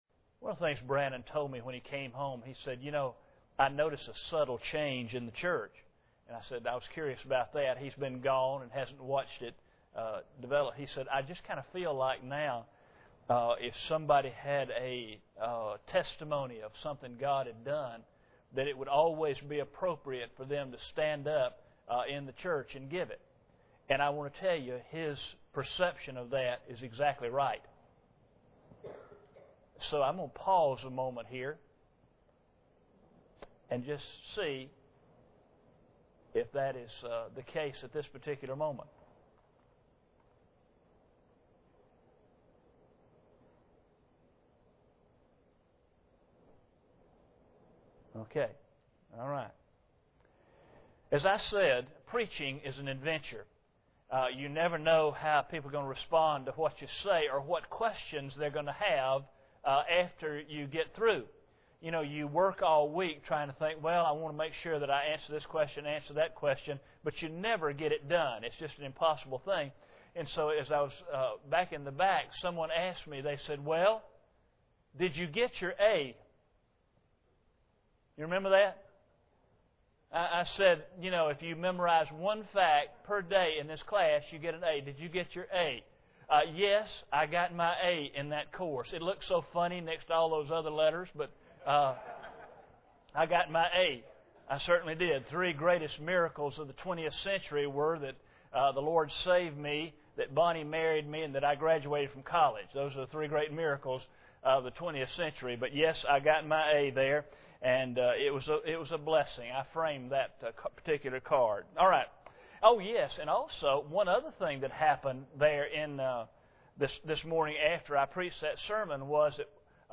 Kings of Judah Service Type: Sunday Evening Preacher